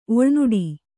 ♪ oḷnuḍi